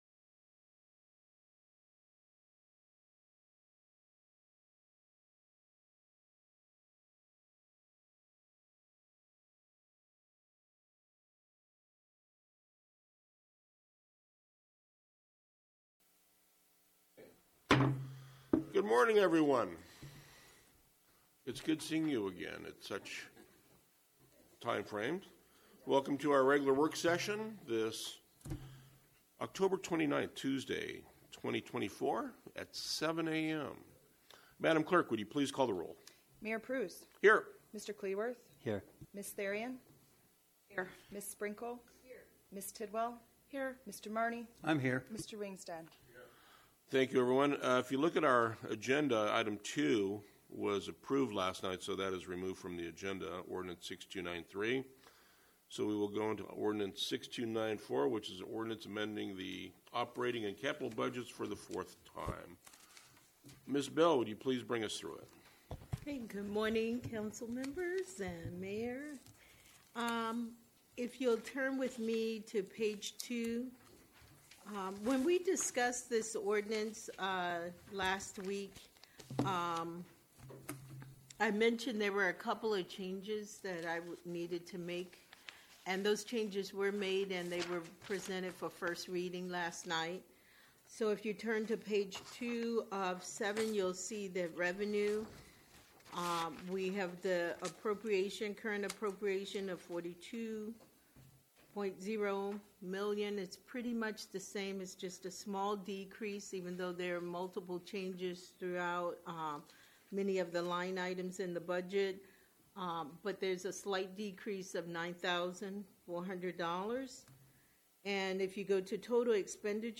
Council Work Session